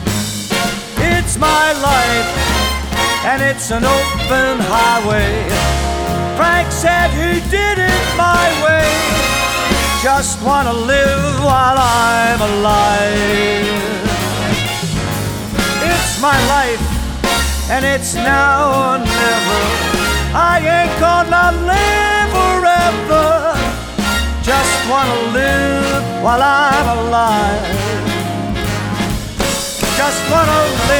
Här kommer ett looptest av DA > mikrofonförstärkare > AD.
Filerna är nivåmatchade med gainkontrollen på mickförstärkarens ingång.